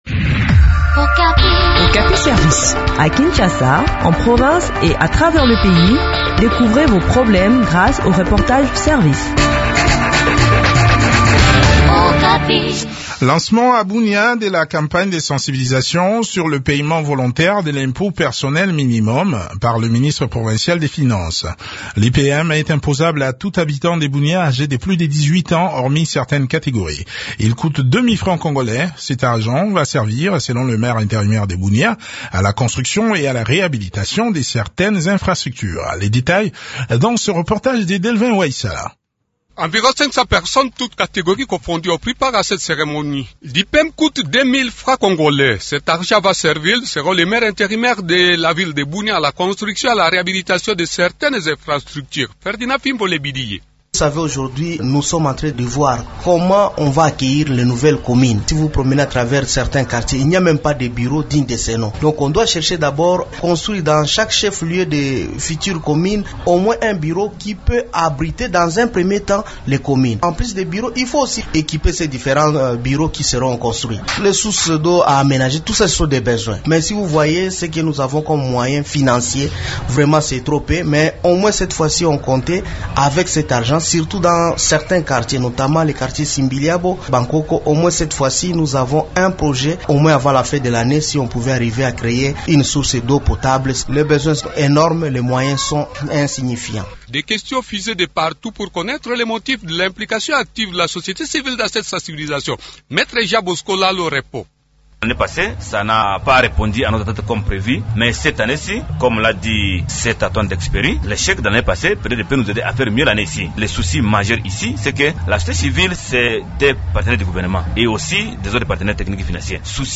Le point sur le lancement de cette campagne dans cet entretien